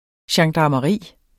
Udtale [ ɕɑŋdɑmʌˈʁiˀ ]